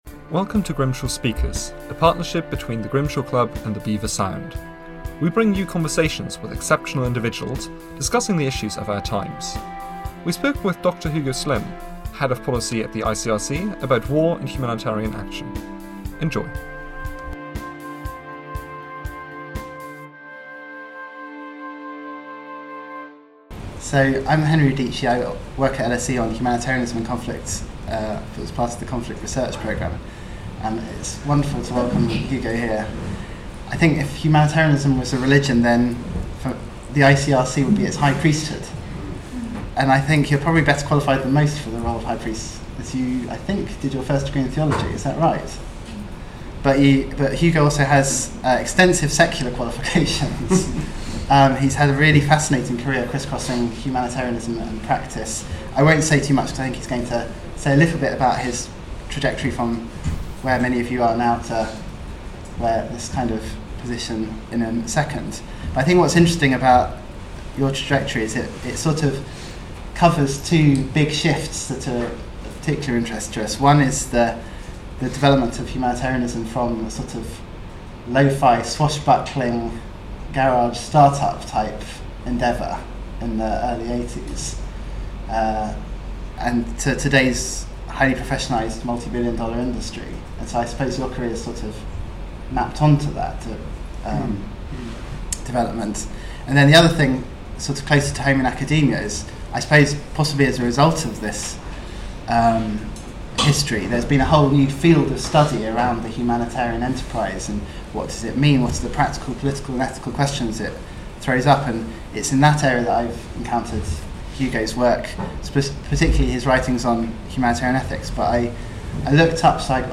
chairs the discussion.
This is Grimshaw Speaker, a partnership between the Grimshaw Club and Beaver Sound. We bring you conversations with exceptional individuals, discussing the issues of our times.